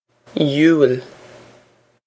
il yoo-il
This is an approximate phonetic pronunciation of the phrase.